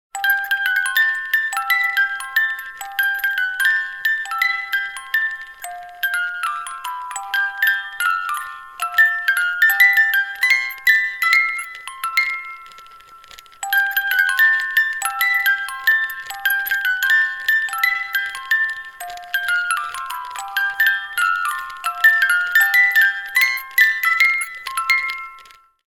Children’s Music Box Melody Sound Effect
Genres: Sound Logo